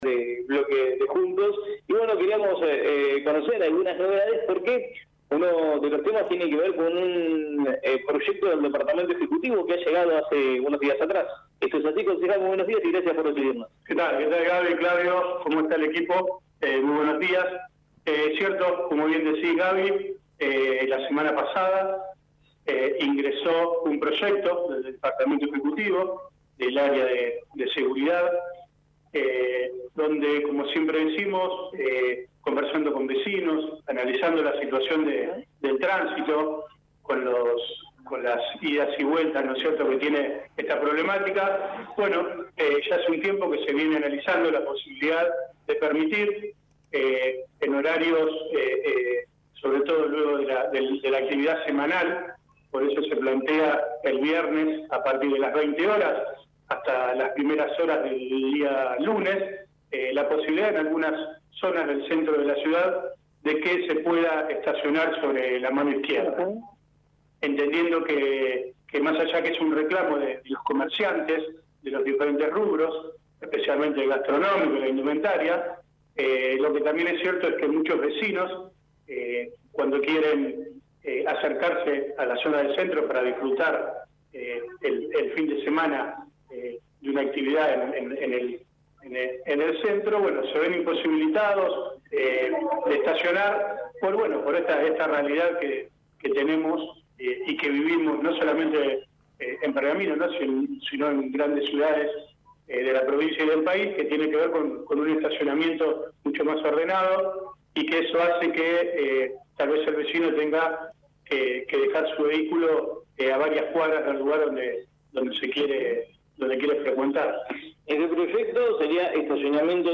Francisco Illia, presidente de la Comisión de Tránsito y Transporte del HCD, dialogó con el móvil de La Mañana de la Radio, y se refirió a un nuevo proyecto elevado por el Ejecutivo y relacionado al Estacionamiento en la ciudad.